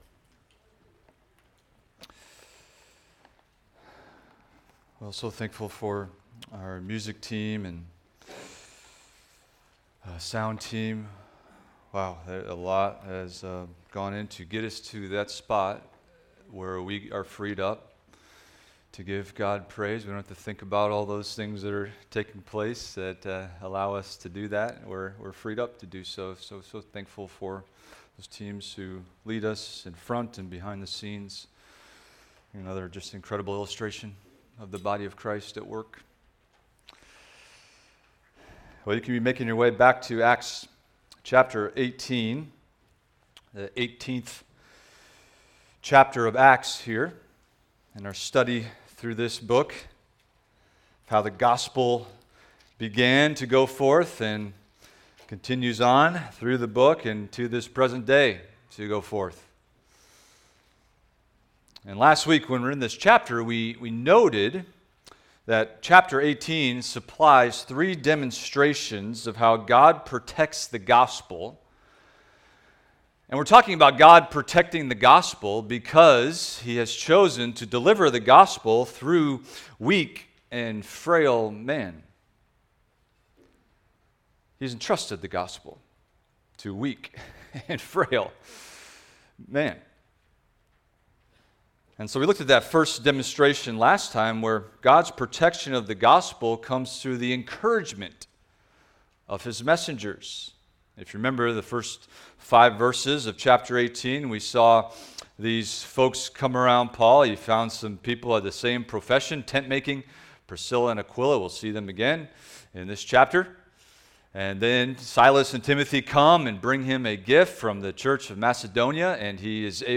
Expository Preaching from the Book of Acts – Acts 18:12-23 - How God Protects His Gospel – Part 2
Expository Preaching through the Book of Acts